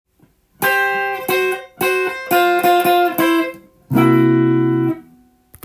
メジャー系フレーズ②
メジャー系②のフレーズはブルースで使用されジャズでも使用されている
osyare.ending4.m4a